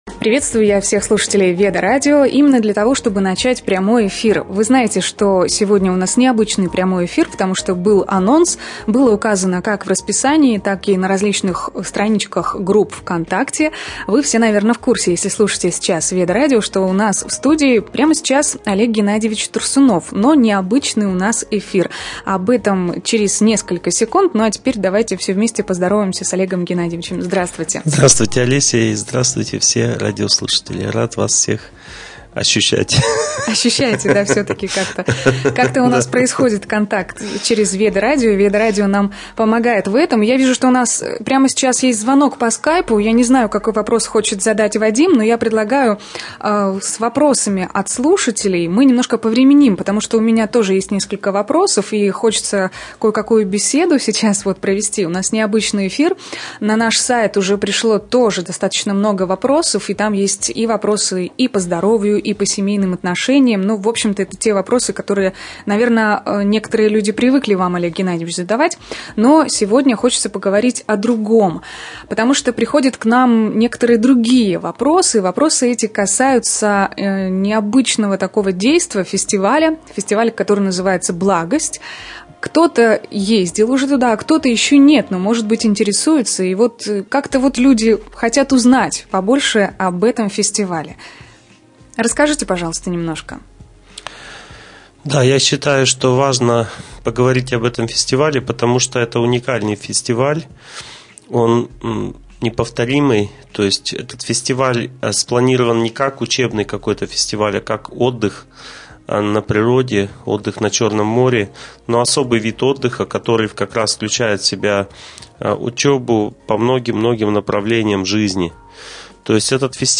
Прямой эфир на Веда-радио (2014)